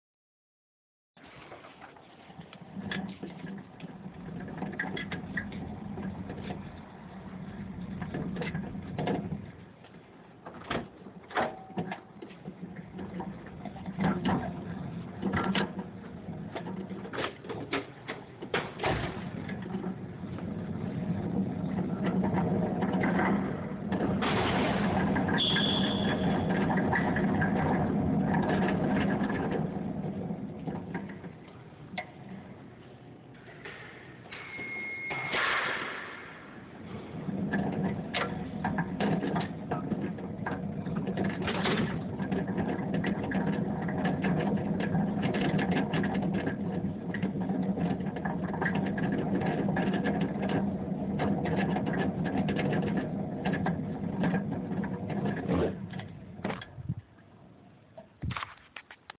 3/7/12 Hofstra Special Collections Dept.
Sounds heard: In the beginning a girl is rustling papers. Then you hear me take a cart through one door and then through another. Then it is pushed down a tile floor hallway and you can hear the rattling of the wheels and the cart. There is a high squeak which is my shoe sole getting caught on the floor. Another door is opened with a loud beeping noise, and the cart is pushed down another area and into an aisle of books.